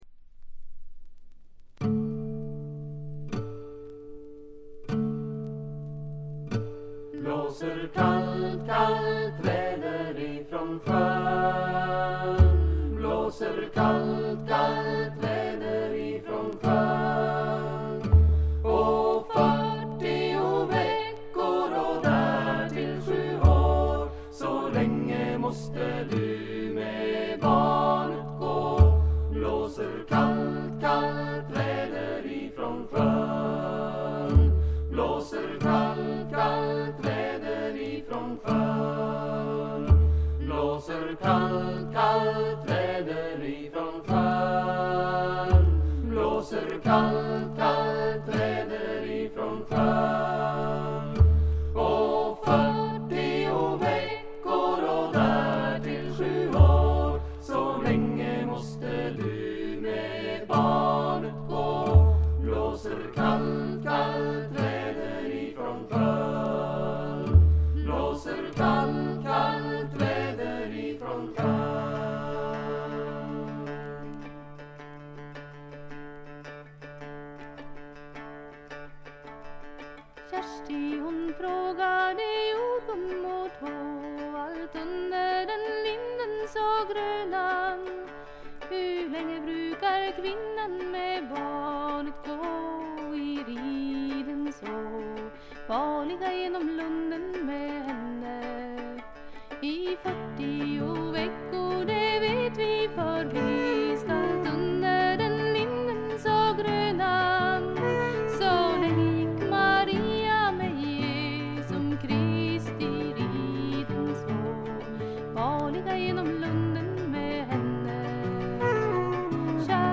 軽微なチリプチ少し。
スウェーデンのトラッド・フォーク・グループ。
試聴曲は現品からの取り込み音源です。
Recorded At - Metronome Studio, Stockholm